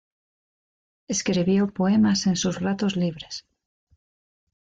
Pronounced as (IPA) /ˈlibɾes/